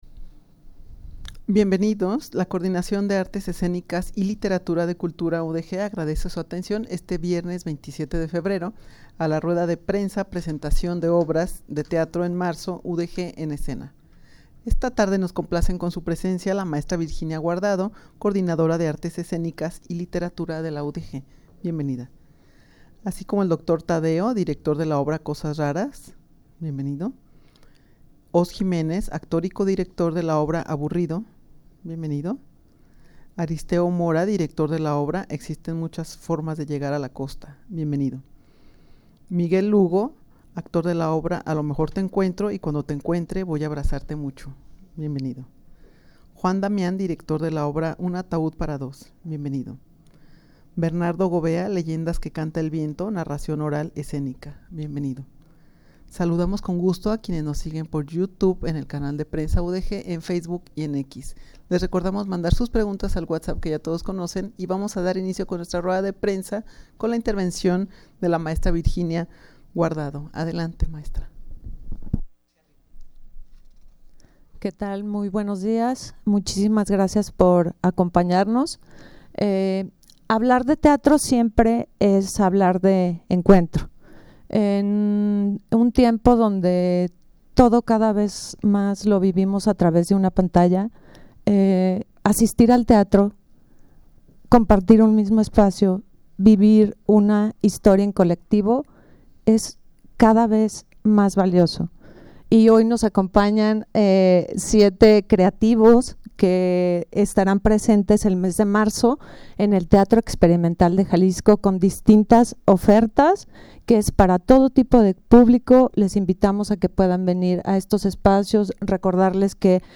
Audio de la Rueda de Prensa
rueda-de-prensa-presentacion-de-obras-de-teatro-en-marzo-udeg-en-escena.mp3